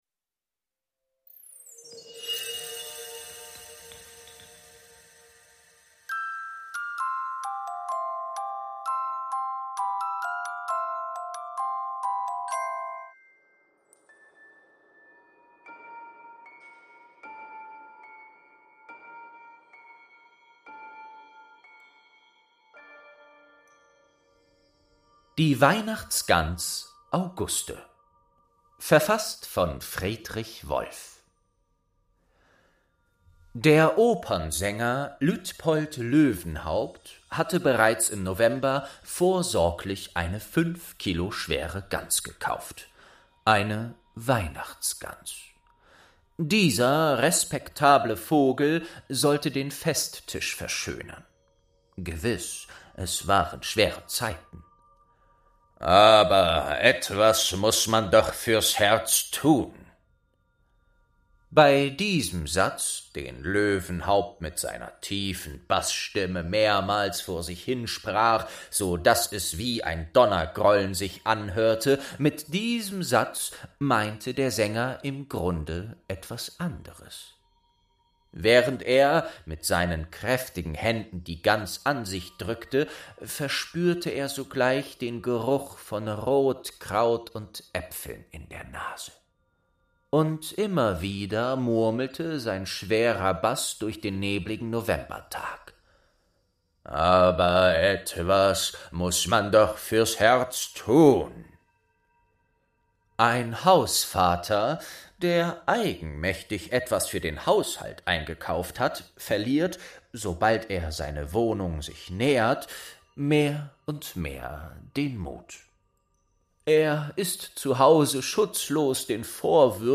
Die Weihnachtsgans Auguste | Staffel 2 ~ Märchen aus der verschollenen Bibliothek - Ein Hörspiel Podcast